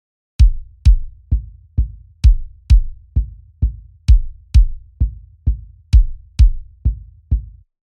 Kick filter HP vs LP
Here’s what those settings in the screens above sound like on the same kick sample (BD Natural from the factory sounds). The beefier one is using HP.